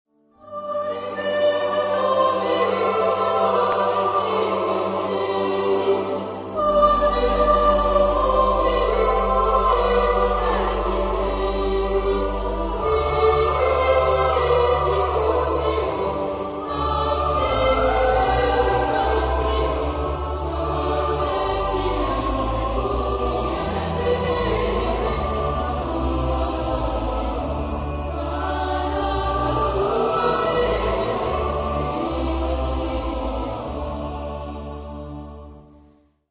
Genre-Style-Form: Choir ; Sacred
Type of Choir: SATB  (4 mixed voices )
Instruments: Organ (1)